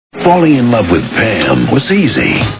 Meet the Parents Movie Sound Bites